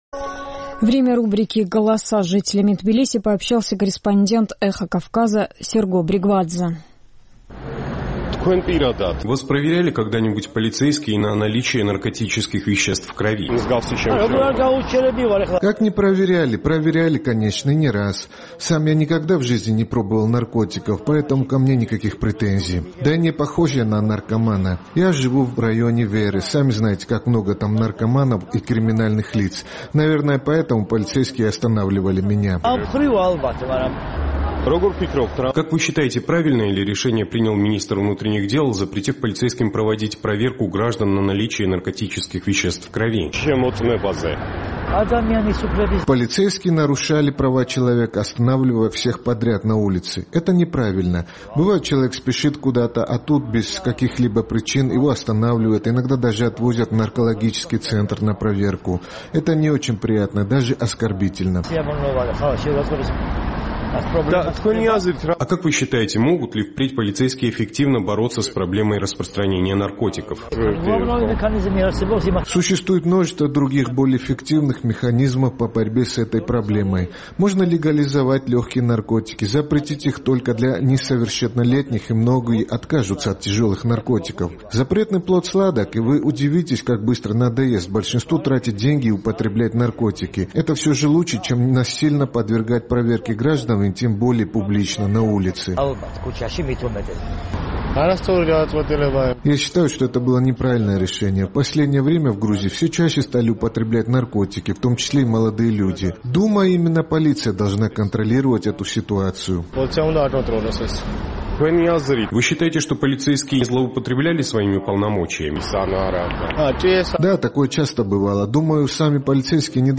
В Грузии полицейские больше не имеют права без повода проверять граждан на наличие наркотических веществ в крови. Как отнеслись к этому решению жители Тбилиси, узнавал наш корреспондент.